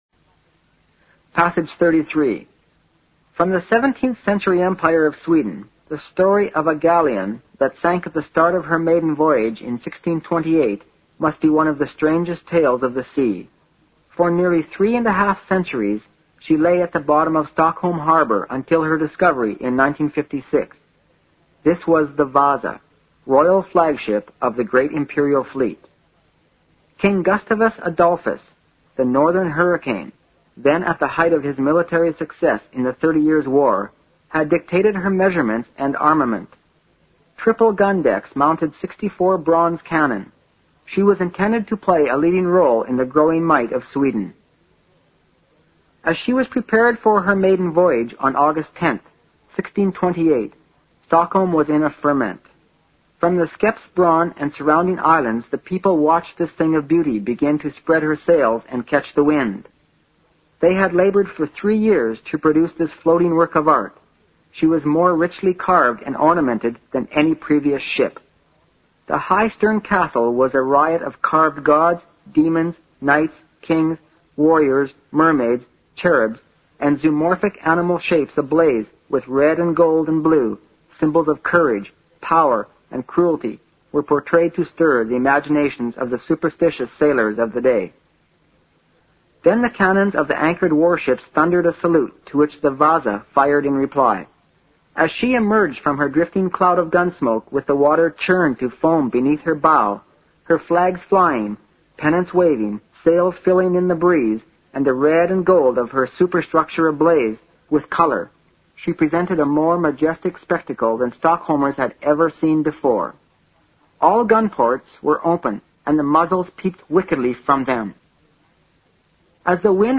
新概念英语85年上外美音版第四册 第33课 听力文件下载—在线英语听力室